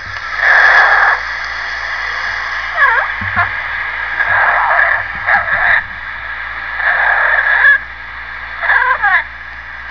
My Blue Fronted Amazon
I have some sounds from Coboo....From his first two months with me
Blowing kisses! / just chattering away!
Ohboy.wav